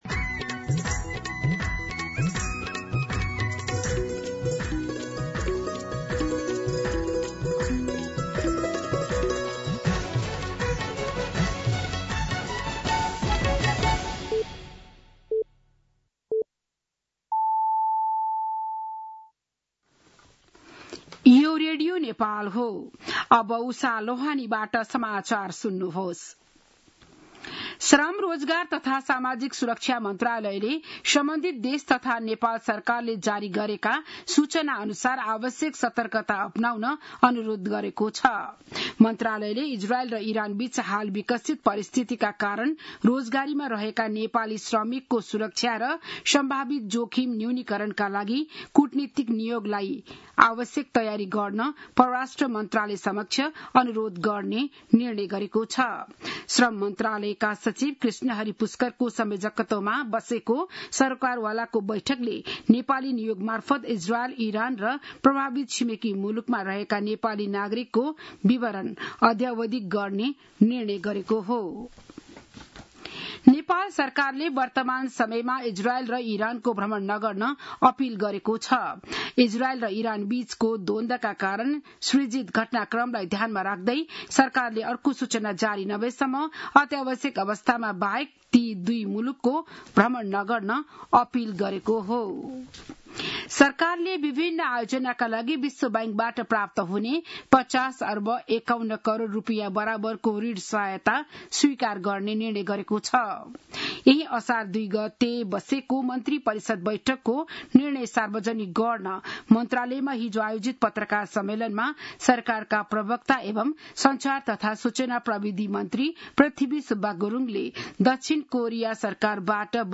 बिहान ११ बजेको नेपाली समाचार : ५ असार , २०८२
11-an-Nepali-News.mp3